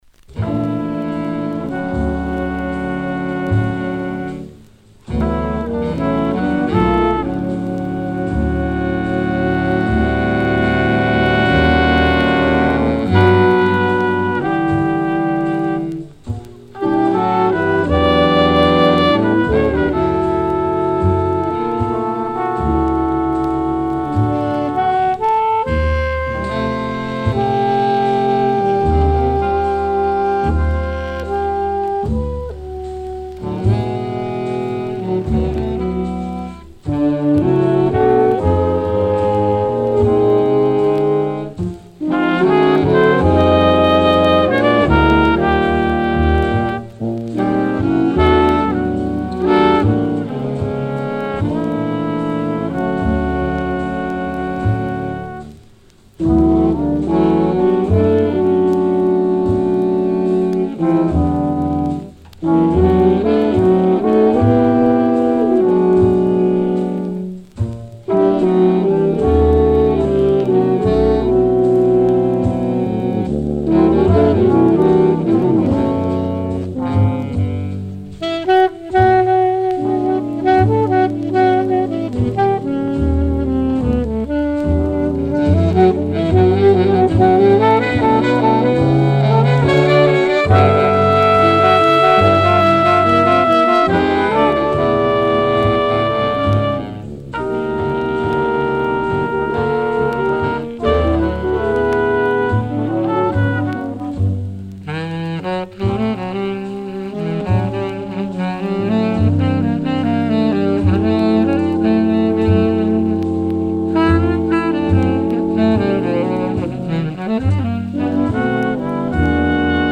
Original 1954 10-inch pressing
All selections recorded in NYC